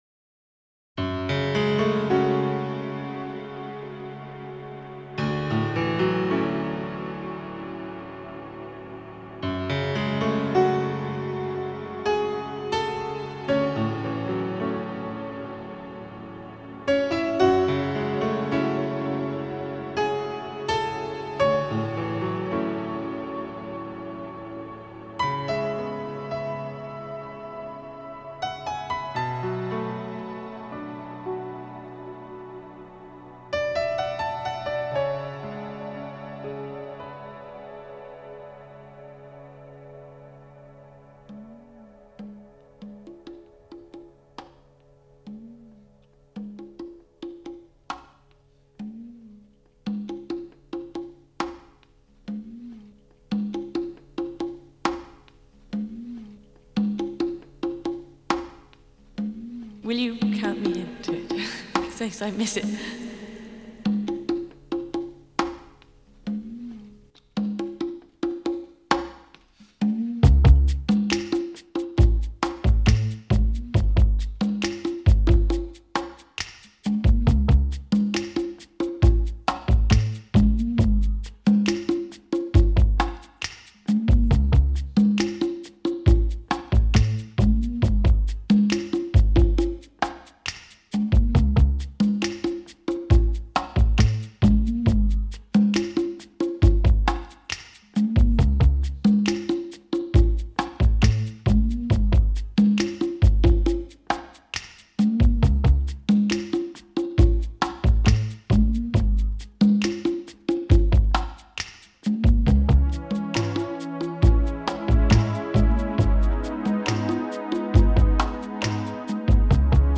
synth-pop